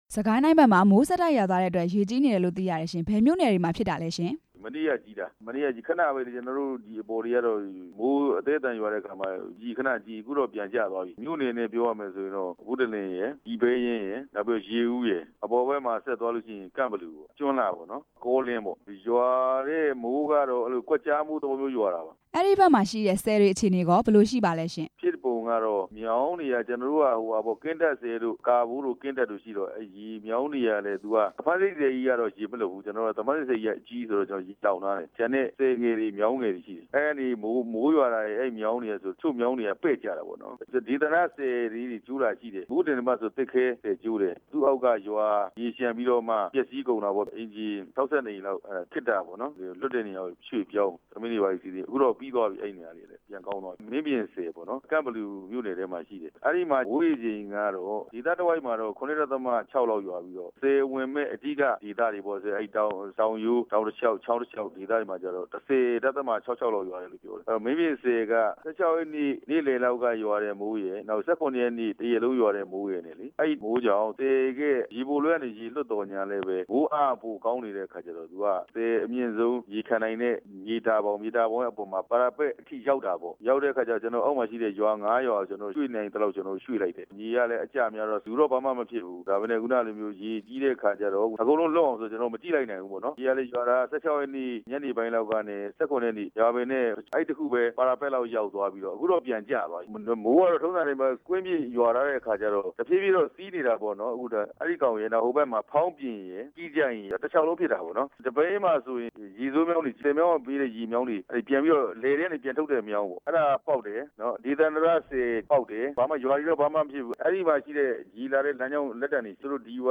စစ်ကိုင်းတိုင်း ရေဘေးအကြောင်း နယ်လုံဝန်ကြီးနဲ့ မေးမြန်းချက်